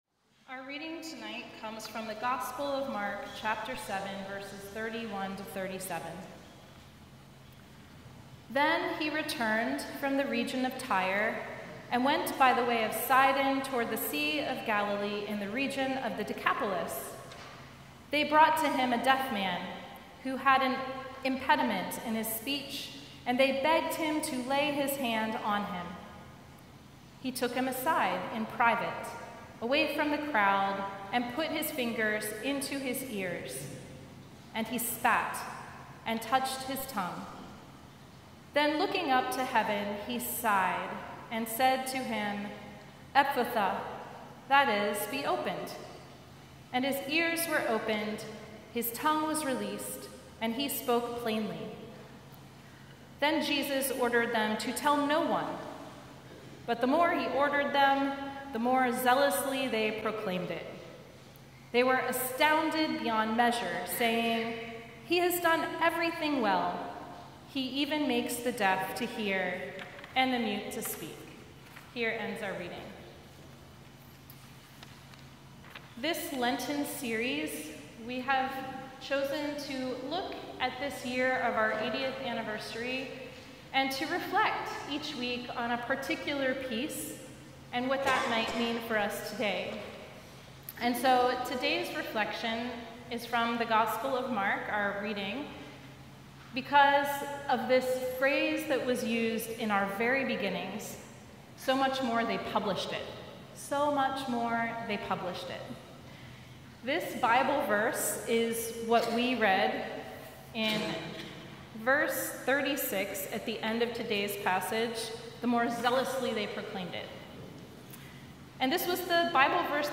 Sermon from Wednesday Evening Prayer in Lent Week 1